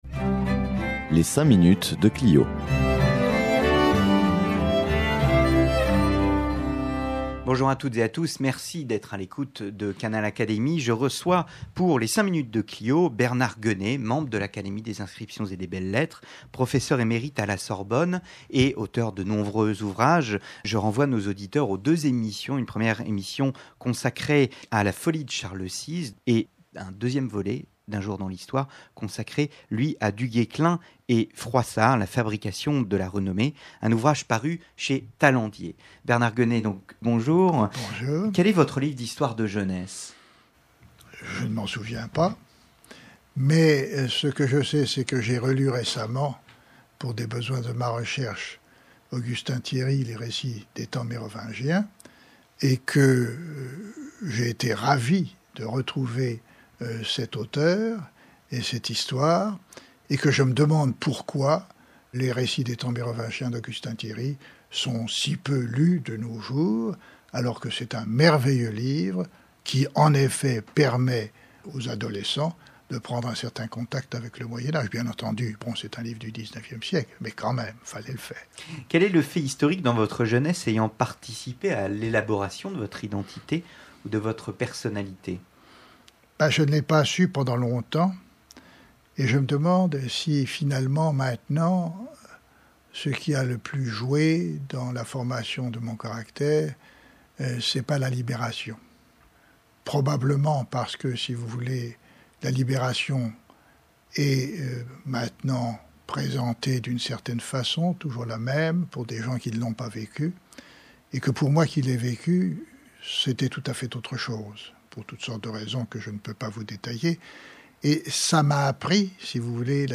Médiéviste, membre de l’Académie des inscriptions et belles-lettres, Bernard Guenée répond au questionnaire des "cinq minutes de Clio".